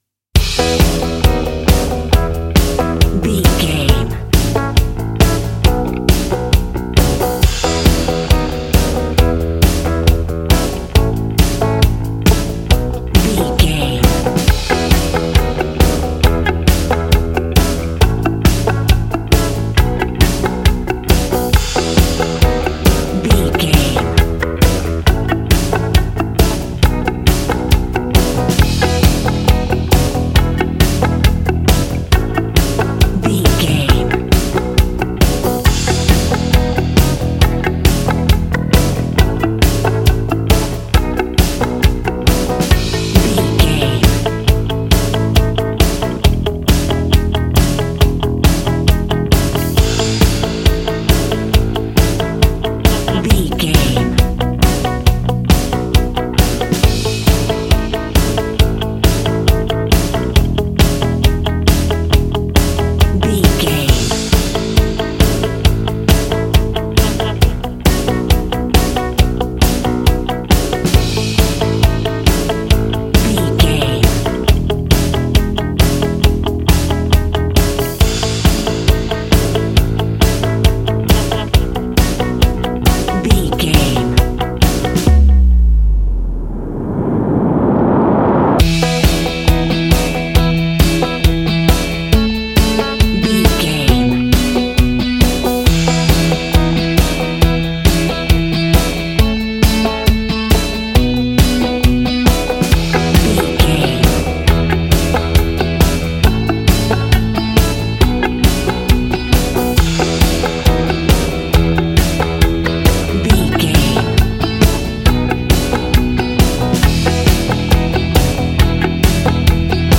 Ionian/Major
groovy
powerful
fun
organ
drums
bass guitar
electric guitar
piano